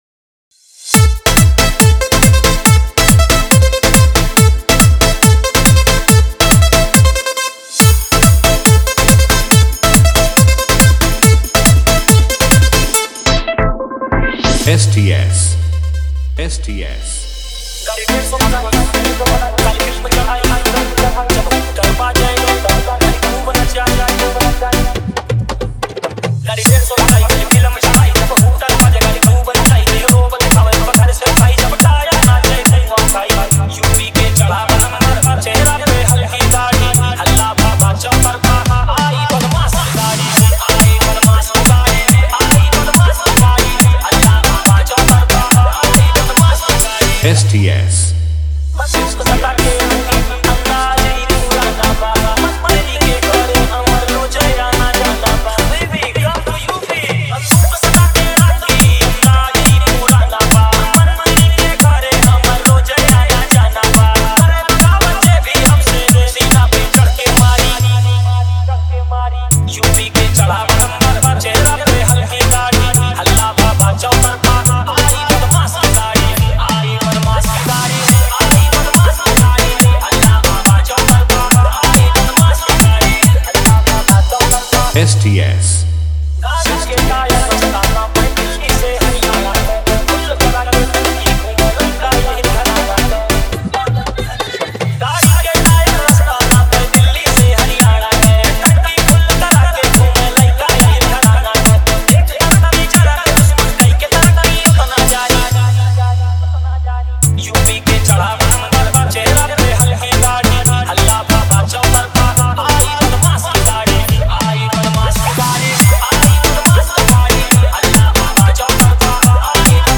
Bhojpuri DJ remix mp3 song
Bhojpuri DJ dance song